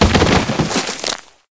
liondead.ogg